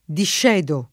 discedere [ dišš $ dere ] v.;